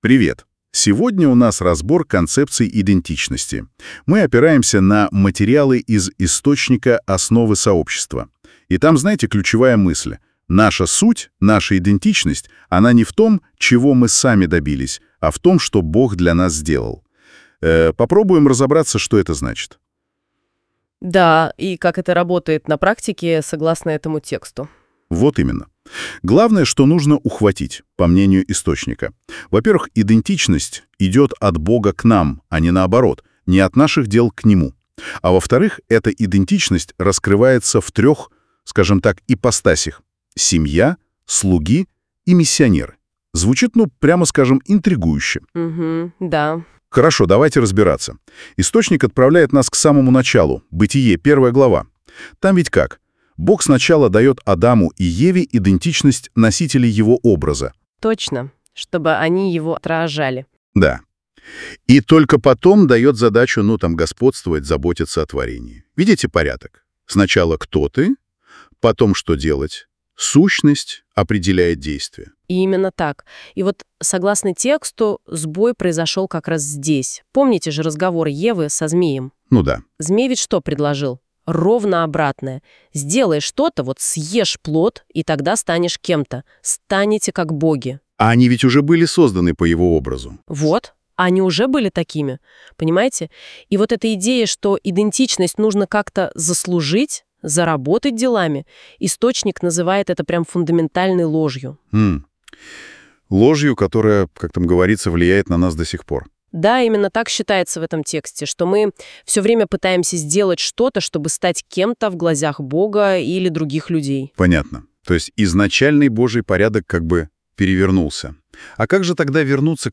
Послушайте разговор наших ведущих о нашей новой идентичности во Христе.